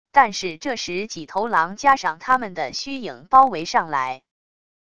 但是这十几头狼加上它们的虚影包围上来wav音频生成系统WAV Audio Player